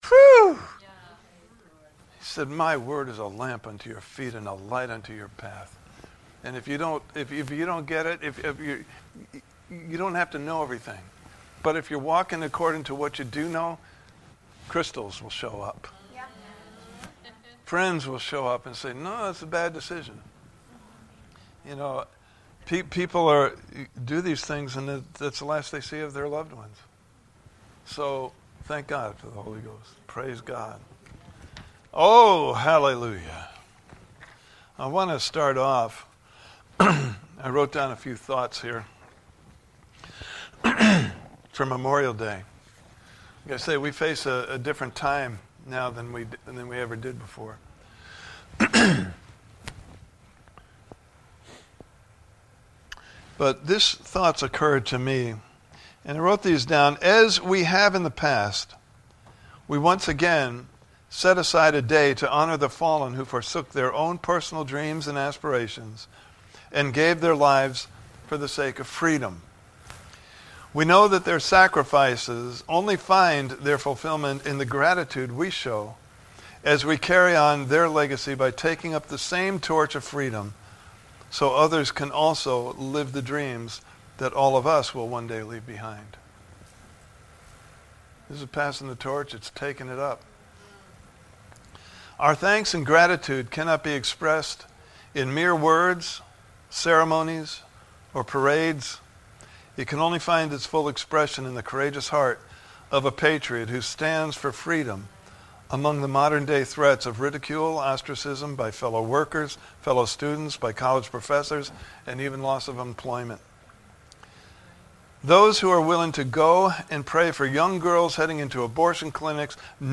Series: The Joy of Our Identity in Christ! Service Type: Sunday Morning Service « Healing Is Your Right!